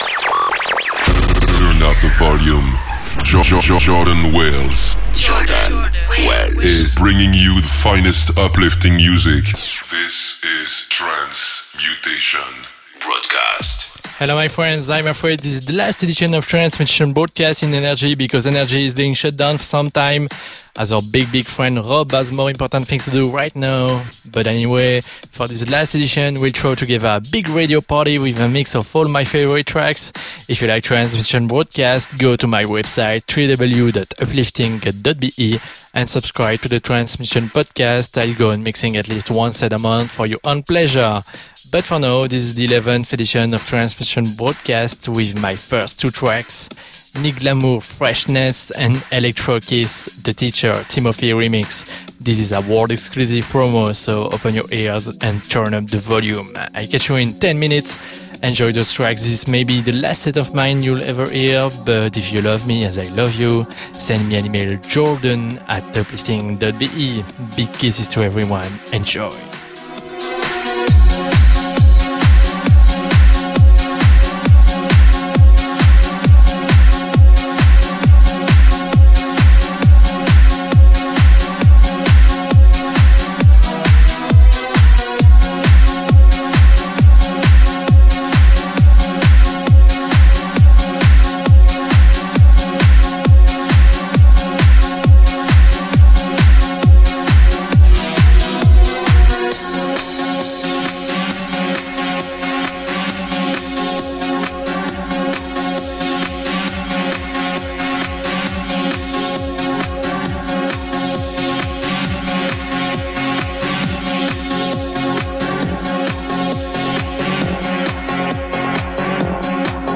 uplifting trance